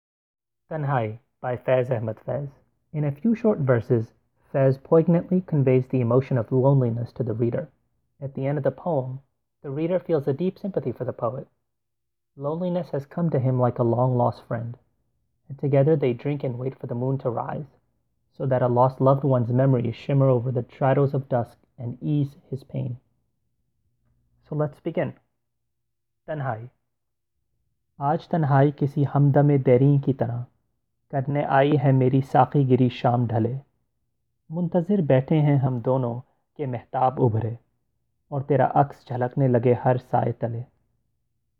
I will read out loud poems, news articles and other selections.
Once you feel you know the words, the second audio recording will be only in Urdu without any English translations.